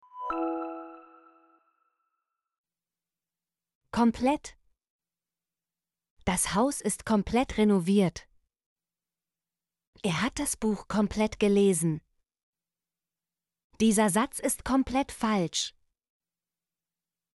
komplett - Example Sentences & Pronunciation, German Frequency List